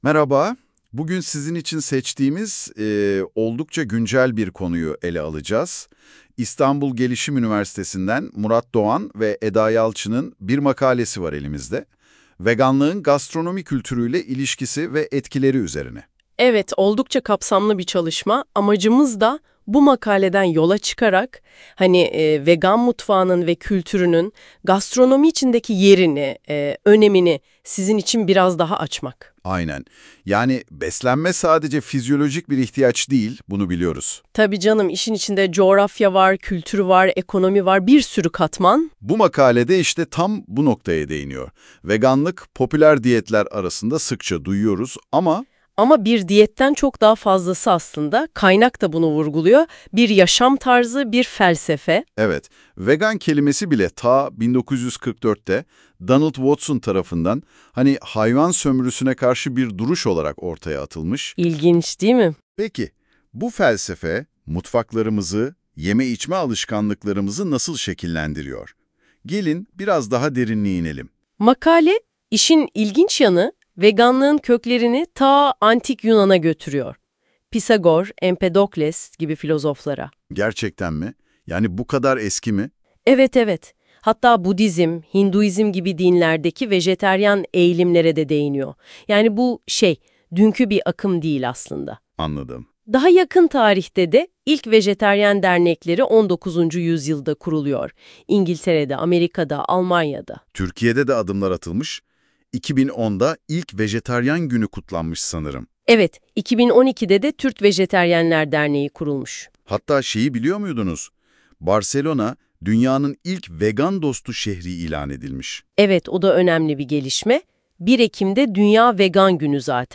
Vegan Mutfağı ve Gastronomi Üzerine Söyleşi
Vegan Mutfağı, Söyleşi